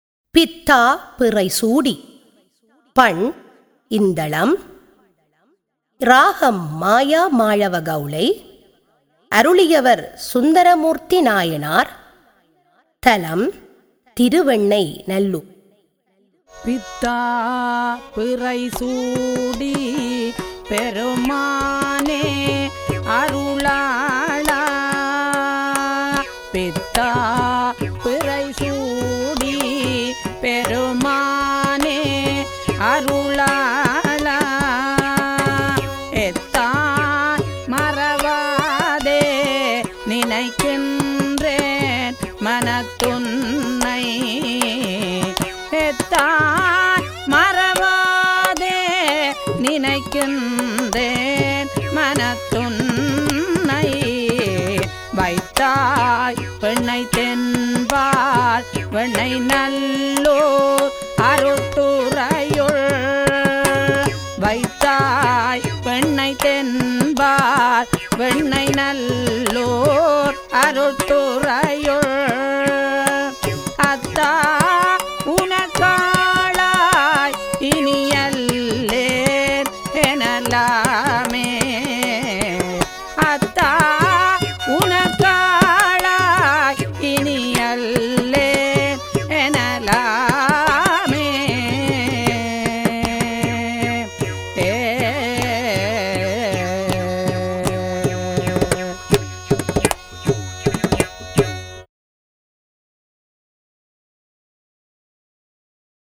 தரம் 6 இல் கல்வி பயிலும் சைவநெறிப் பாடத்தை கற்கும் மணவர்களின் நன்மை கருதி அவர்கள் தேவாரங்களை இலகுவாக மனனம் செய்யும் நோக்கில் இசைவடிவாக்கம் செய்யப்பட்ட தேவாரப்பாடல்கள் இங்கே பதிவிடபட்டுள்ளன.